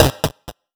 Blip 003.wav